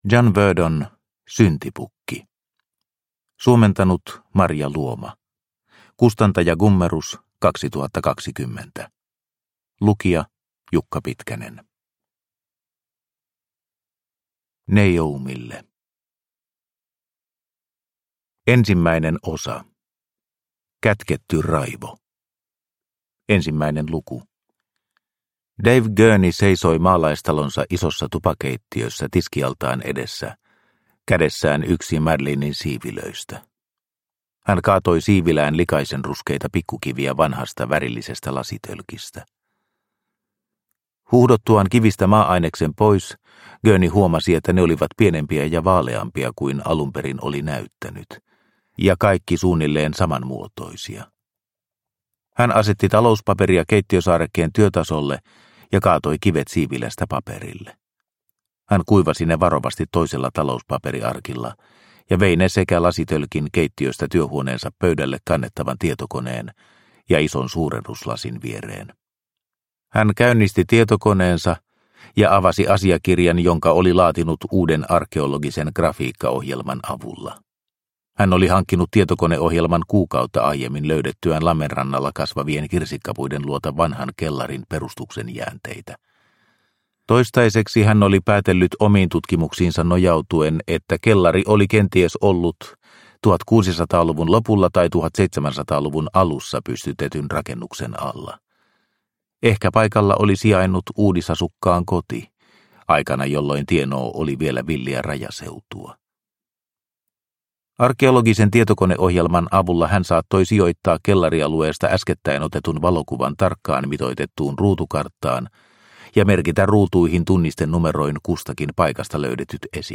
Syntipukki – Ljudbok – Laddas ner